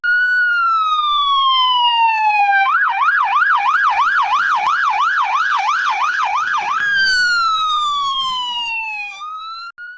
In this work, we introduce SonicMotion, the first end-to-end latent diffusion framework capable of generating FOA audio with explicit control over moving sound sources.
Prompt: "A police siren wails from the front and slowly moves counter-clockwise to the back"